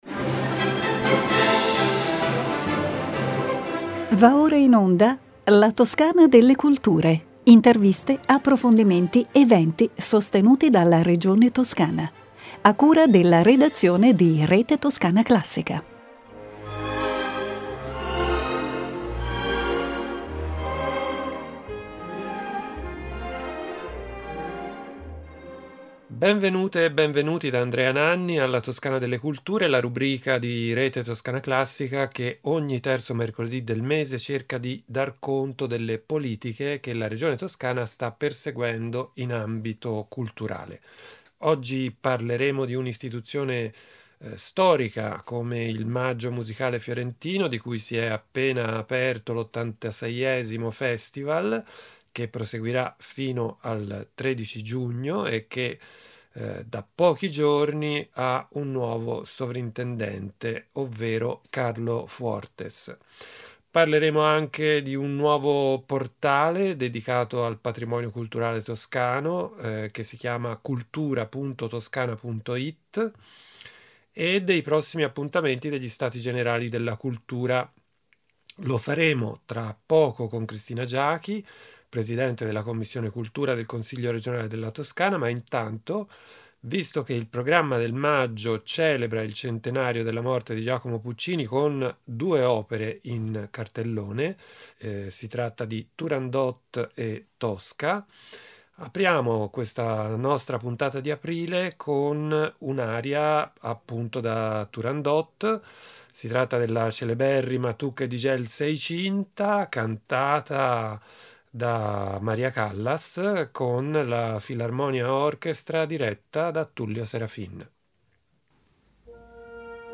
Il patrimonio culturale toscano in tutte le sue declinazione in un nuovo portale regionale tutto da scoprire. La 12esima edizione del Premio Narrativa Giovane di Nuova Antologia promosso dalla Fondazione Spadolini. I prossimi appuntamenti degli Stati Generali della Cultura. Questi gli argomenti di cui parliamo con Cristina Giachi, Presidente della Quinta commissione del Consiglio regionale della Toscana.